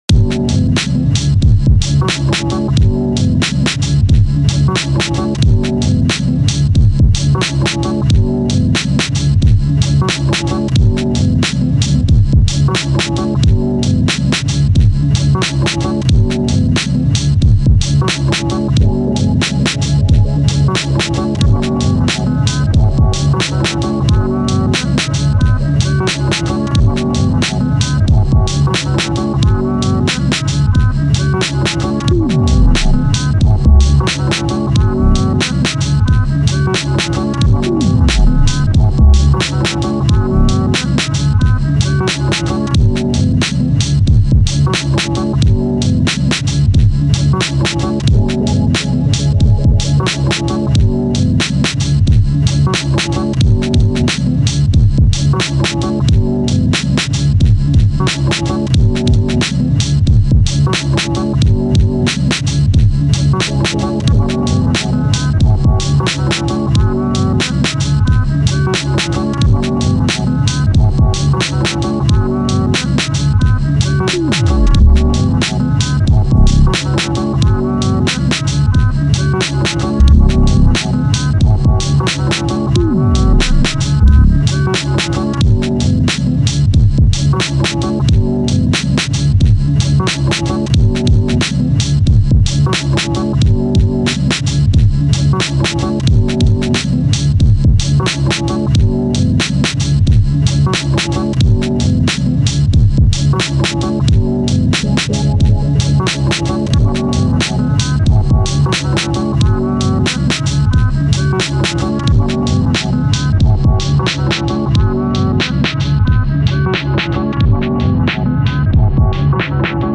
Live takes recording into Ableton triggering one shots and effects so it’s sloppy and not mixed or anything, Fun experimenting though.
Cheers mate! Yeah the second one comes in a bit bang bang levels wise after the first :hear_no_evil:
Lots of energy in that beat and a dope melody crafted with the guitar sounding sample! :beers: